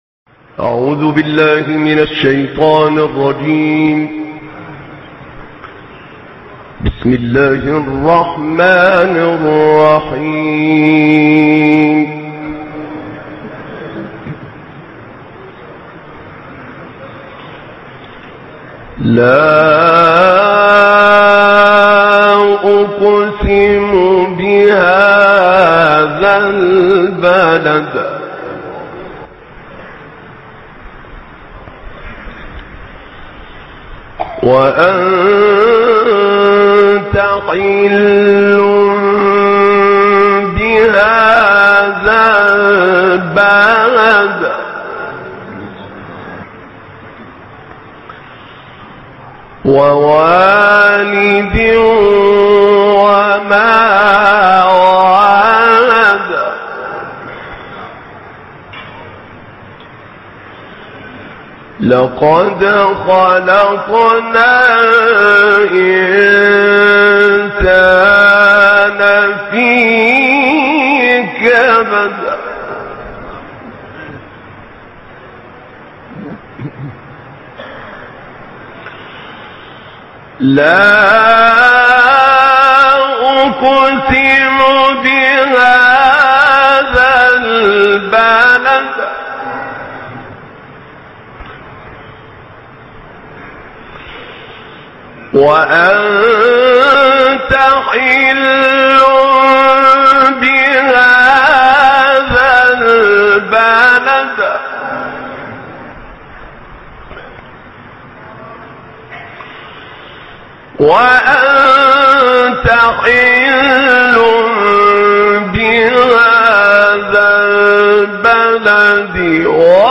تلاوت سوره بلد استاد عبدالفتاح طاروطی | نغمات قرآن | دانلود تلاوت قرآن